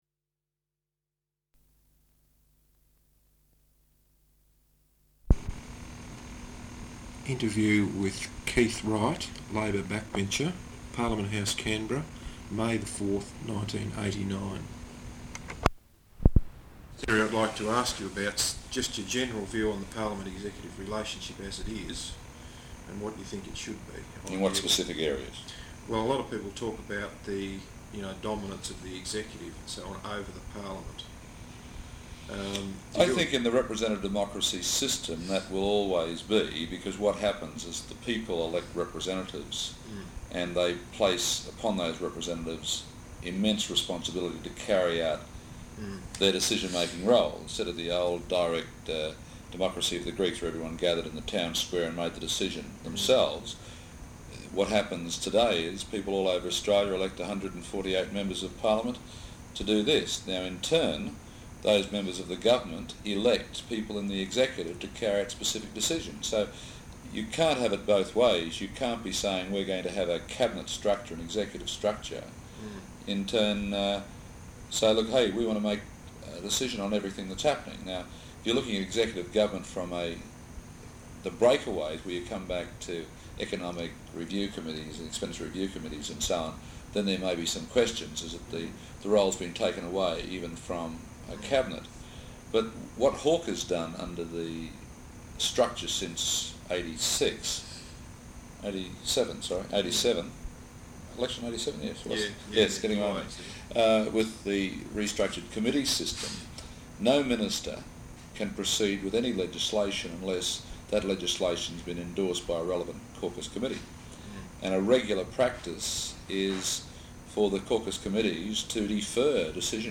Interview with Keith Wright, Labor Backbencher, Parliament House, Canberra, on 4 May 1989.